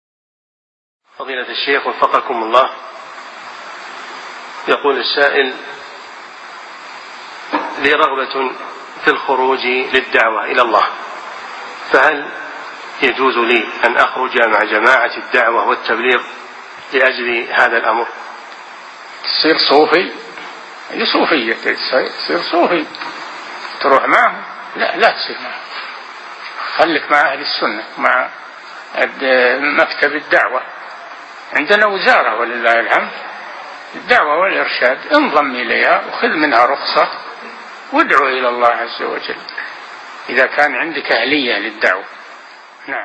Download audio file Downloaded: 1087 Played: 1739 Artist: العلامة الشيخ صالح الفوزان حفظه الله Title: جماعة التبليغ صوفية ومن يخرج معهم صوفي Album: موقع النهج الواضح Length: 0:43 minutes (246.31 KB) Format: MP3 Mono 22kHz 32Kbps (VBR)